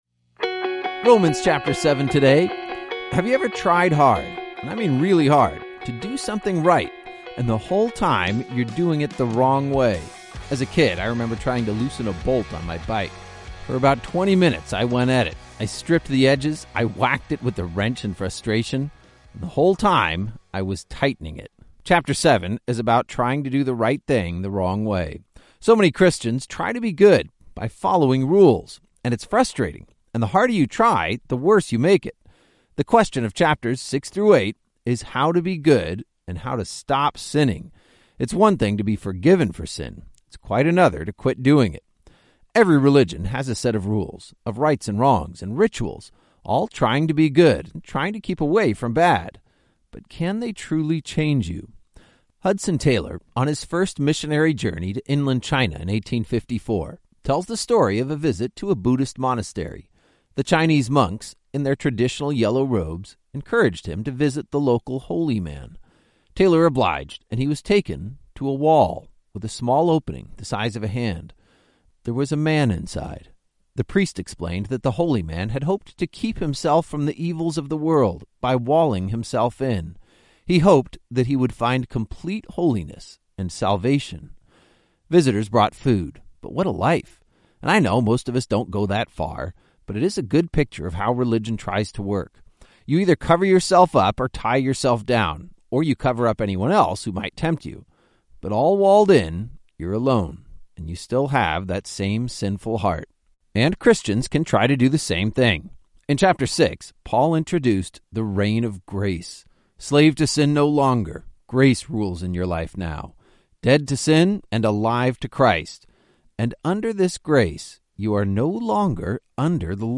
19 Journeys is a daily audio guide to the entire Bible, one chapter at a time. Each journey takes you on an epic adventure through several Bible books, as your favorite pastors clearly explain each chapter in under ten minutes.